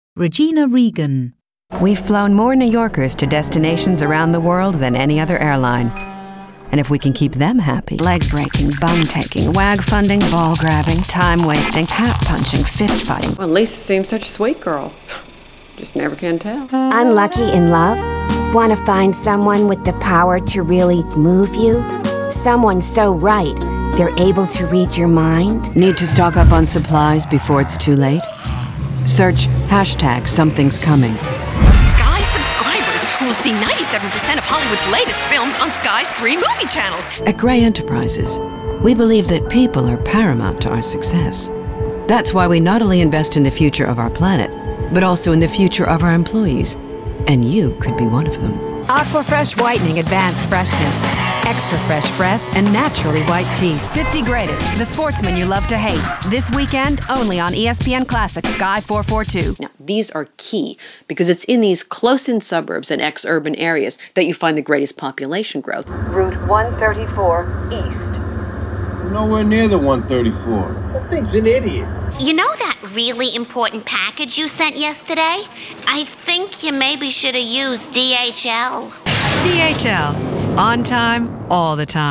Description: American: clear, expressive, compelling
Age range: 40s - 50s
Commercial 0:00 / 0:00
American*, Boston, New York